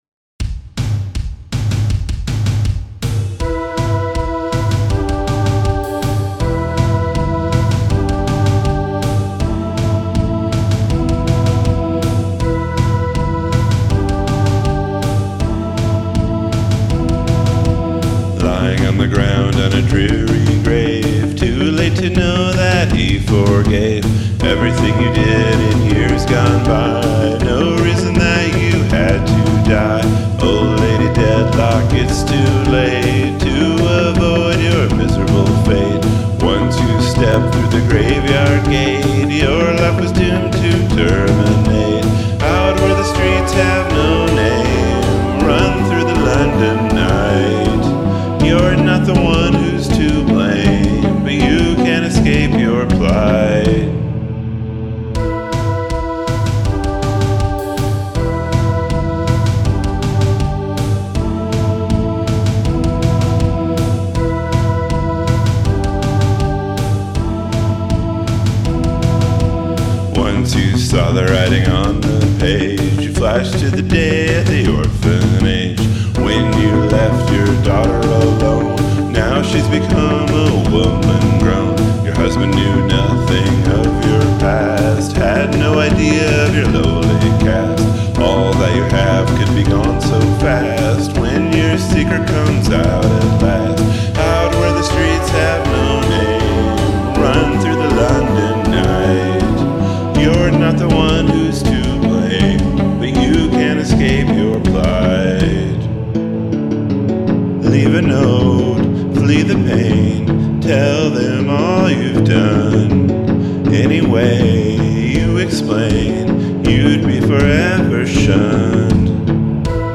Similar groove.